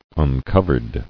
[un·cov·ered]